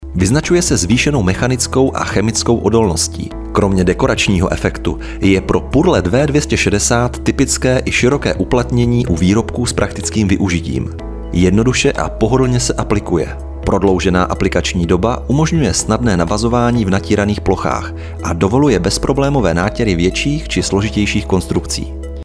Mužský voiceover do Vašich videí
Nabízím mužský voiceover středního věku.